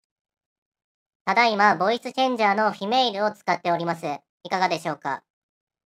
AmpliGame SC3には「Male」「Female」「Robot」「Monster」「Baby」「Elder」のボイスチェンジ機能があるので、試しに「Male」「Female」を使って録音してみました。
「Female」の音声
ボイスチェンジは普通にクオリティ高いと思いました。音質も良いですし、実用性は高そうです。
sc3-female.mp3